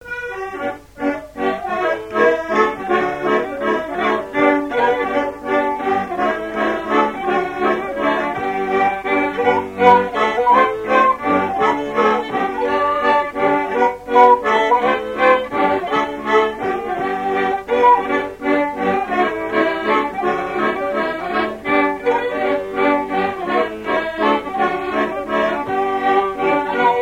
danse : mazurka
Pièce musicale inédite